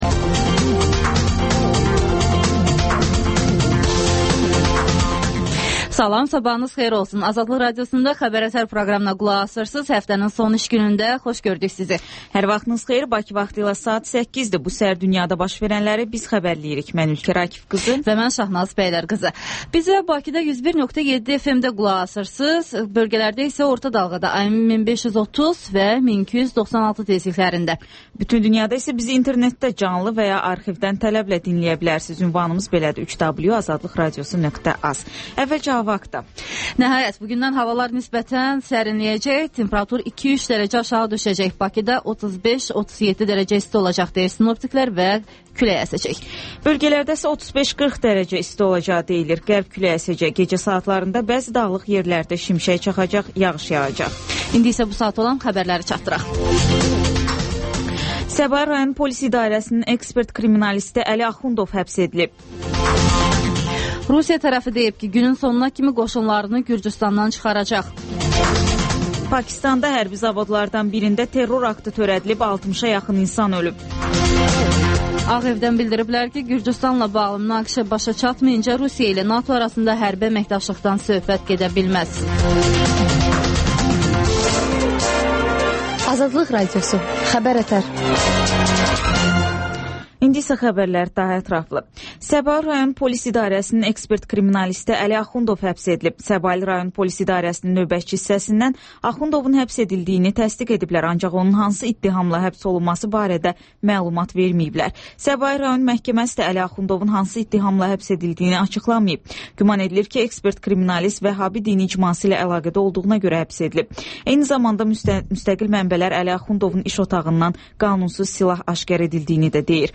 Xəbər-ətər: xəbərlər, müsahibələr və TANINMIŞLAR verilişi: Ölkənin tanınmış simalarıyla söhbət